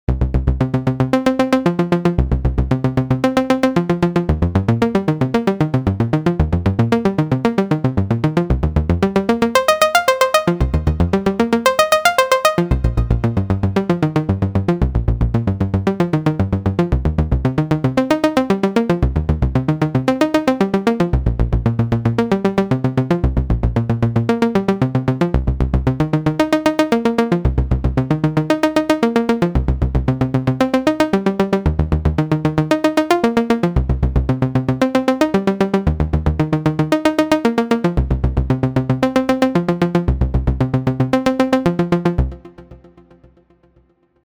The instruments are controlled by MBSEQV4L itself, drums have been added on some samples, they are played by an external drum machine and synchronized via MIDI clock to MBSEQV4L.
LFO: the LFO effect varies note value, velocity and length based on a periodic sine or saw waveform which spans 16 or up to 64 steps.
In this demo different LFO modes are selected at 0:04 - the result sounds like typical "berlin school" sequences, and we will get them with a single push on the button!
mbseqv4l_demo_lfo1.mp3